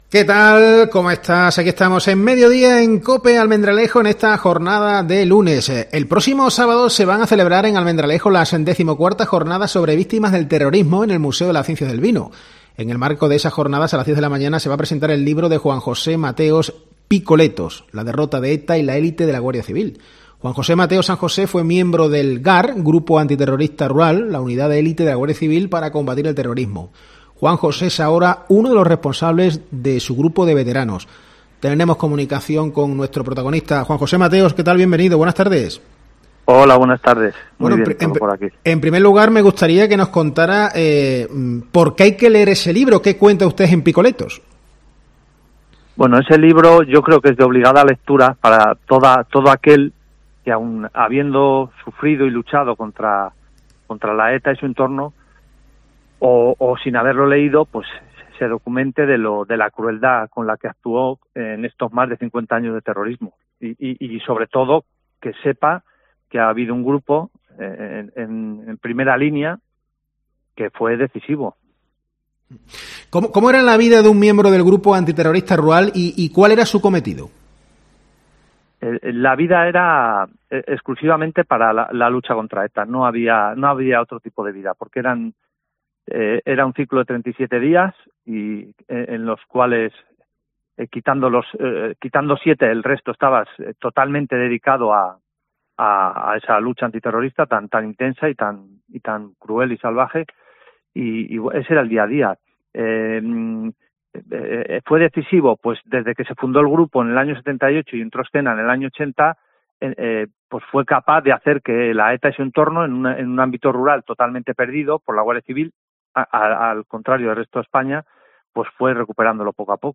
En COPE, hemos hablado con él.